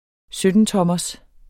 Udtale [ ˈsødənˌtʌmʌs ]